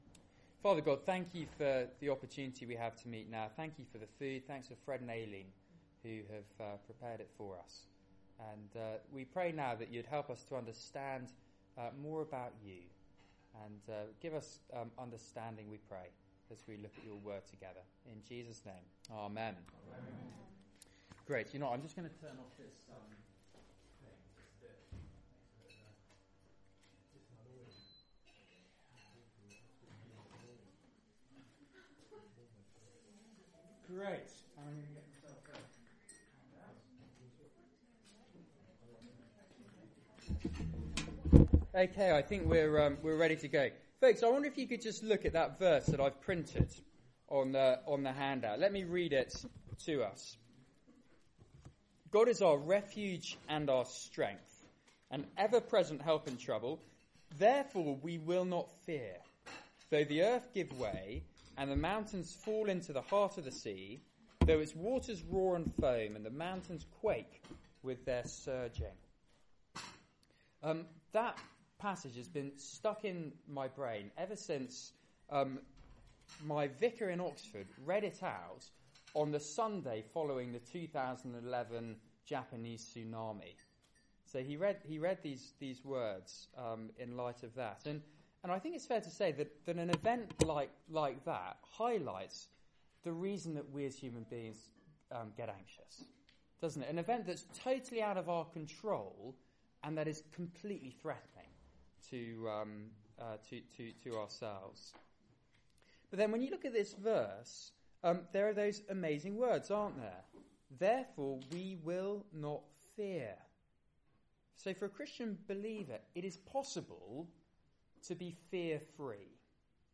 Media for Seminar on Thu 27th Nov 2014 19:30 Speaker